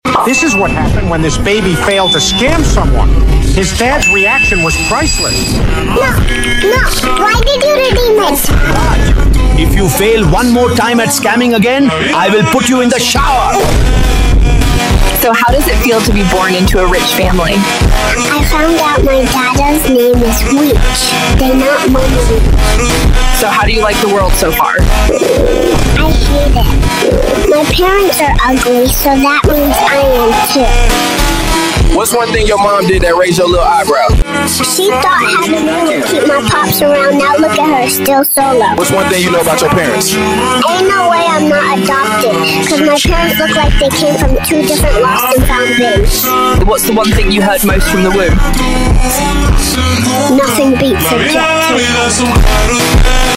This video features original commentary, fast-paced edits, and hilarious rankings designed to keep you hooked from start to finish.